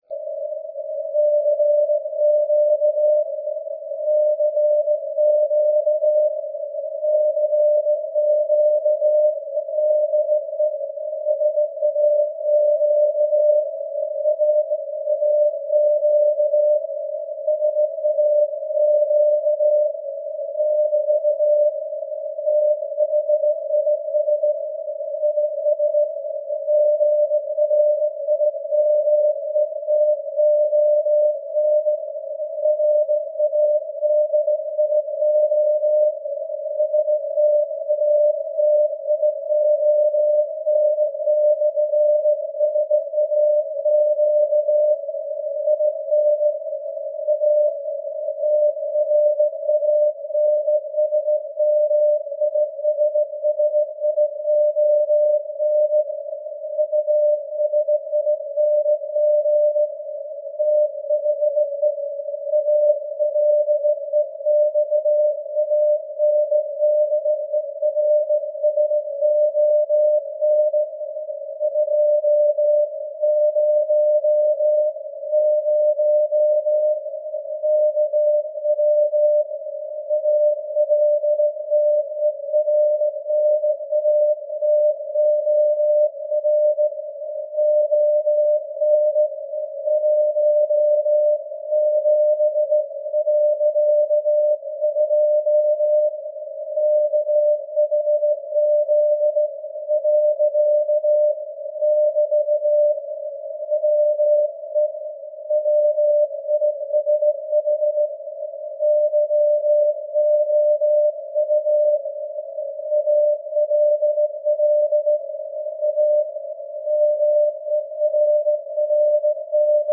The 17.2 kHz SAQ transmission was received using the Elad S2 receiver, that can easily go down to 10 kHz.
The antenna was a modified mini whip, with a Crystalonics CP644 FET used instead of the usual J310.